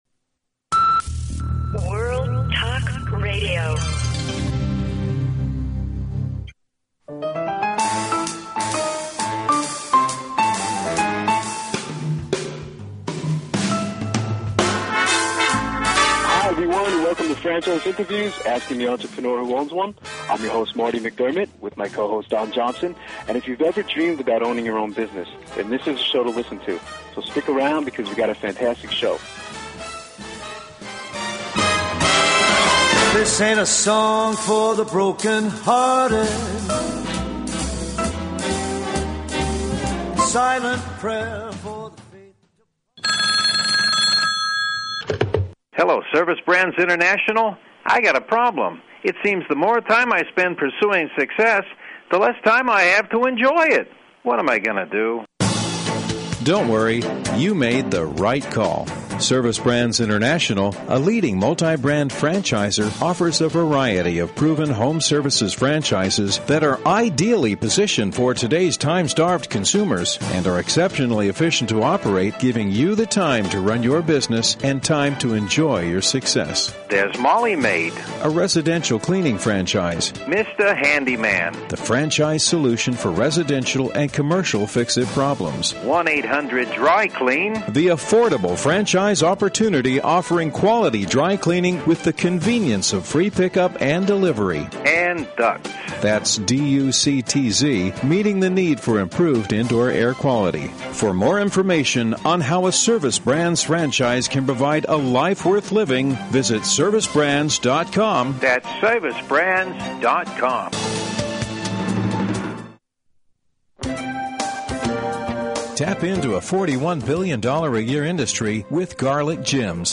Franchise Interviews meets with the Garlic Jim's franchise opportunity